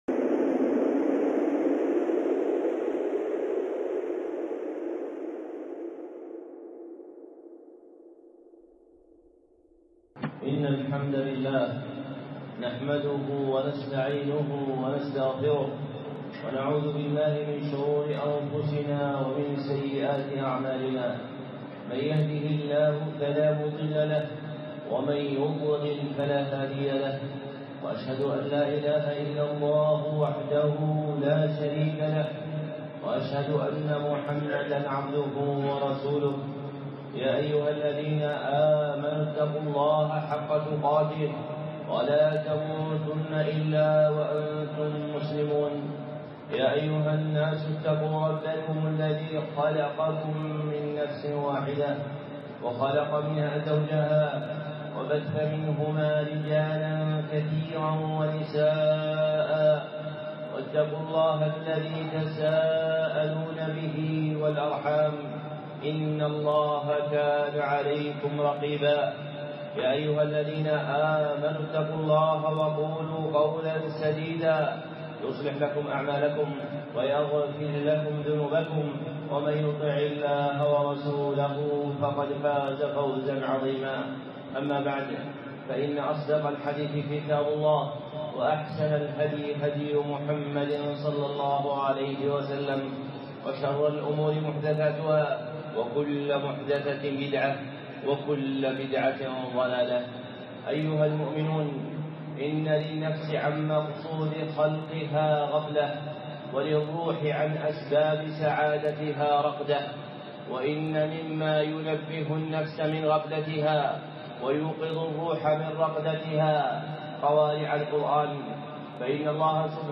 خطبة (قوارع القرآن
الخطب المنبرية